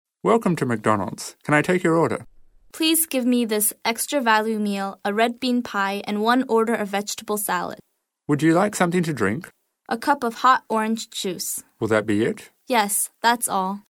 英语口语900句 02.04.对话.1.在麦当劳 听力文件下载—在线英语听力室